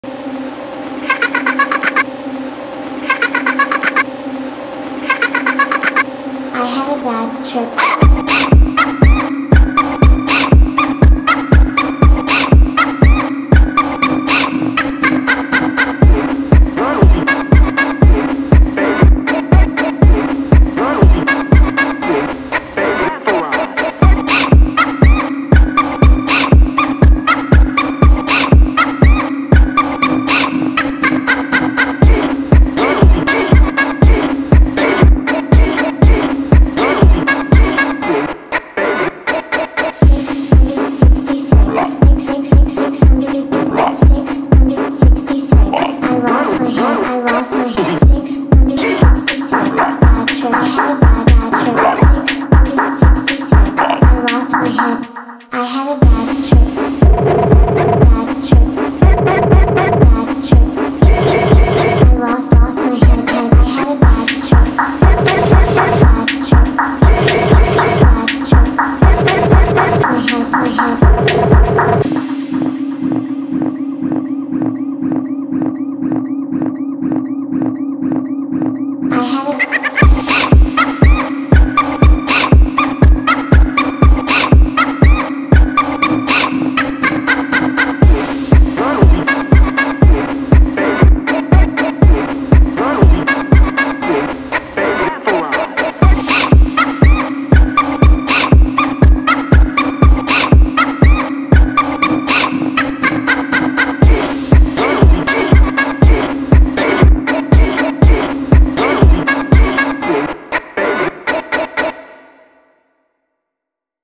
сочетая элементы пост-хардкора и ню-метала.